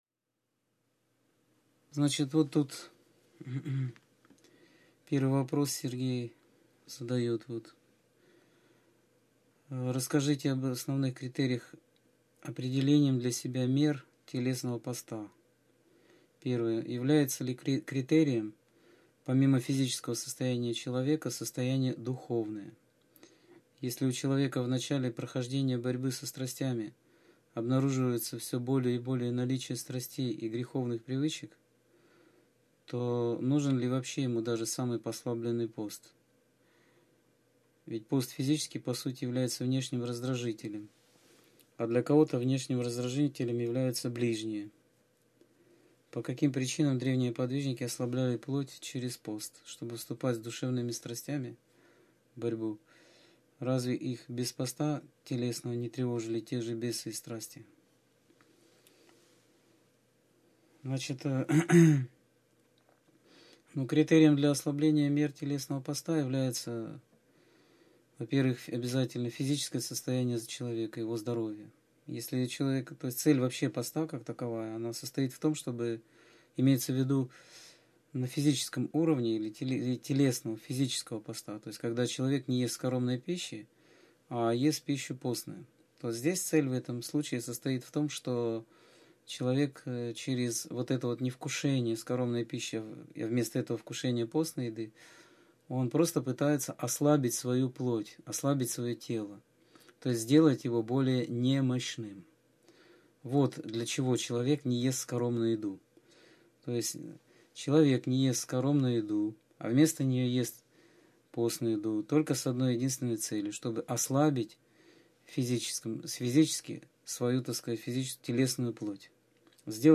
Скайп-беседа 14.12.2013